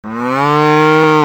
cow.mp3